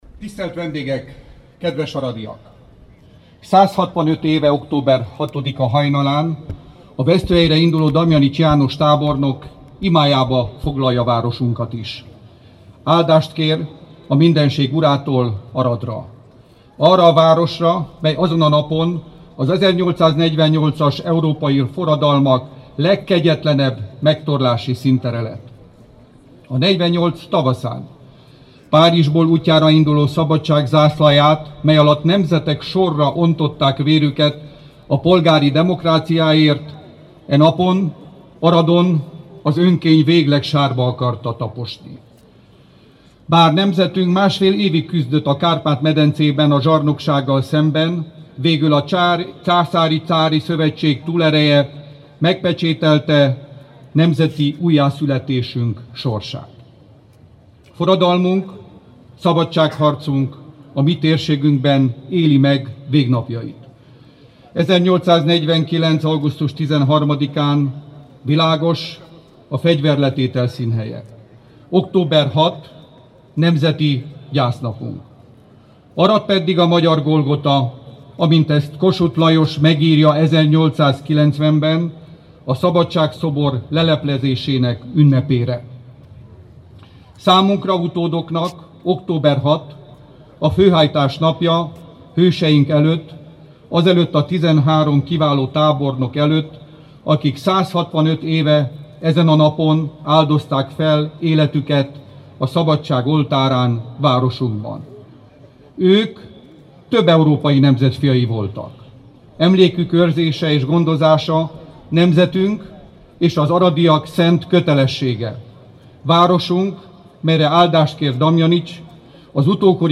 Kölcsönös tiszteletet várunk el – Bognár Levente beszéde október 6-án [AUDIÓ]